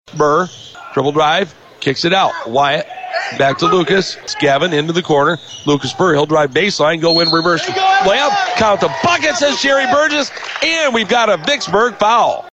(audio courtesy of Fox Sports Radio 99.7 FM/1230 AM)